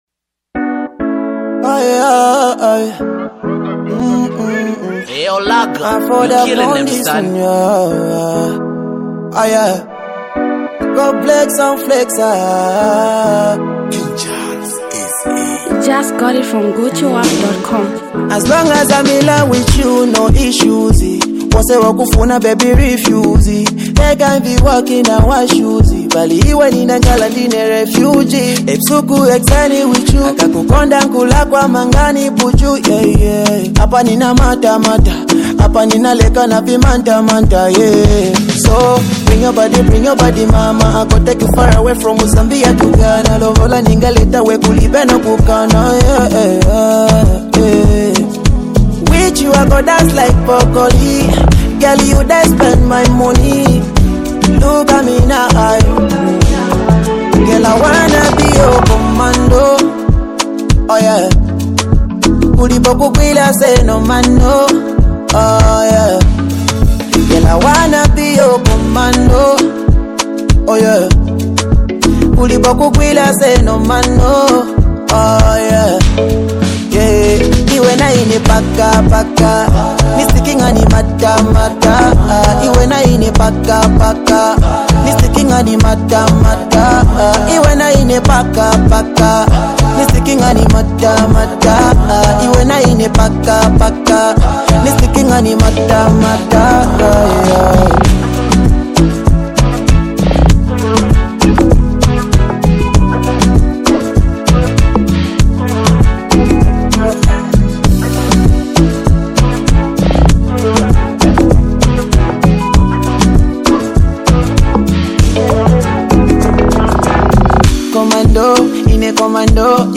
Sonorous vibrations pulse through